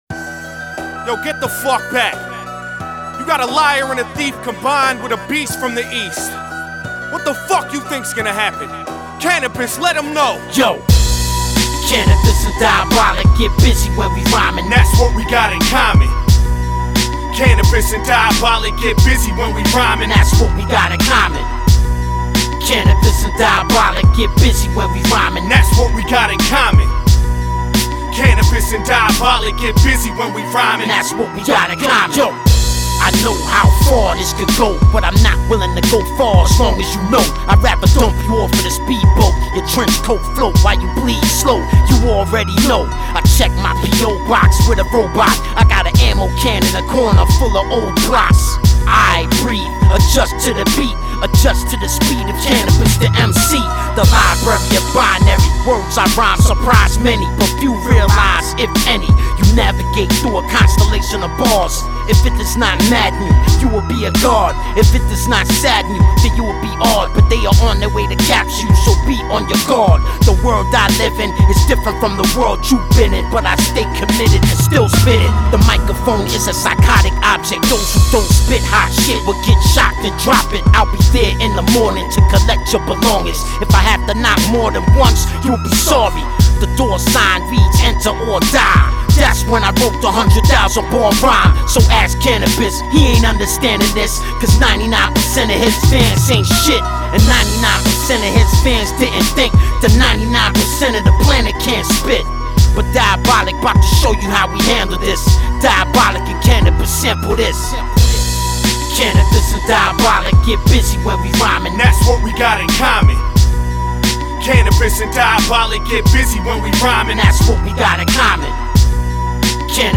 Назад в (rap)...